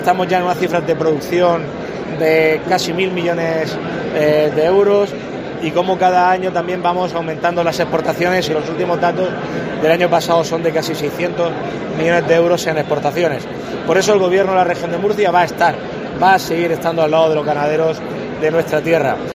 Fernando López Miras, presidente de la CARM en inauguración de SEPOR